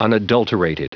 Prononciation du mot unadulterated en anglais (fichier audio)
Prononciation du mot : unadulterated